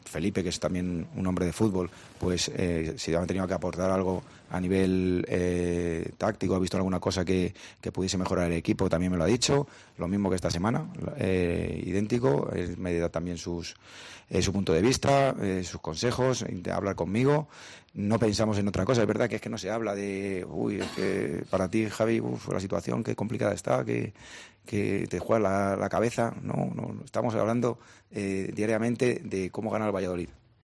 No, hablamos diariamente esta semana de cómo ganar”, dijo Calleja en la rueda de prensa previa al partido.